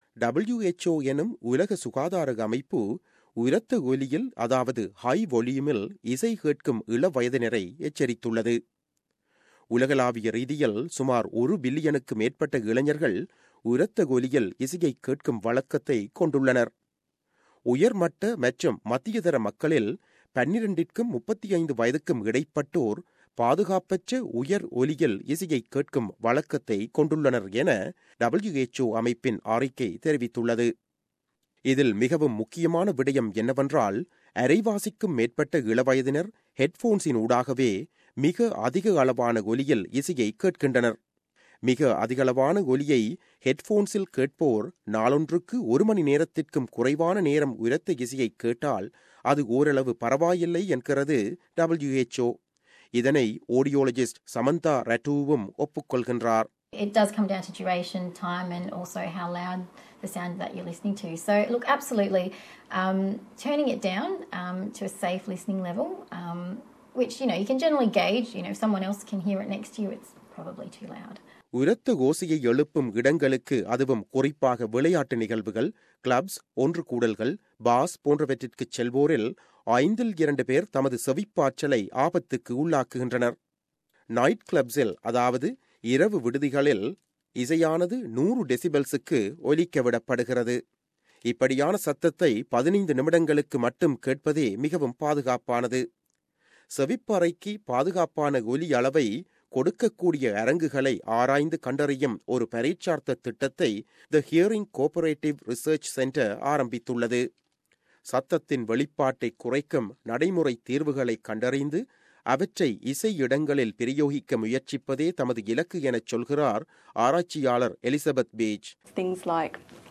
செய்தி விவரணம்